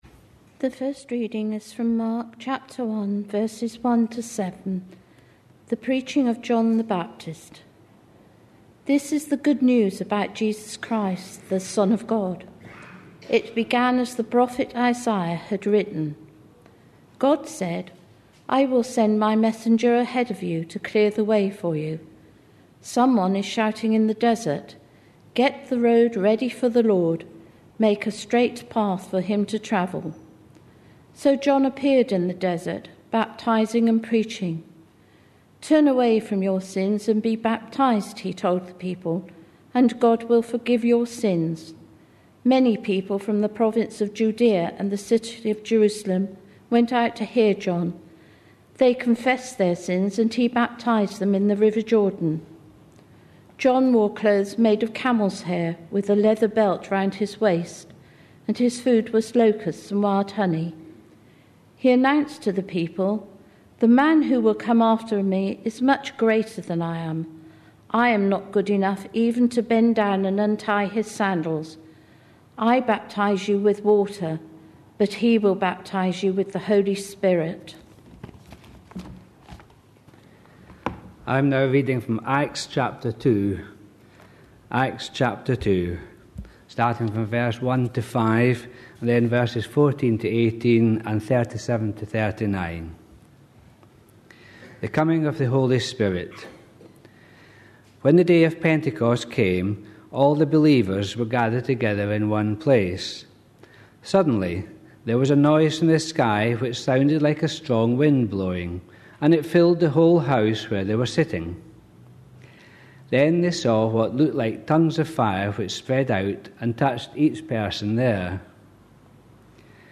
A sermon preached on 23rd May, 2010, as part of our Acts series.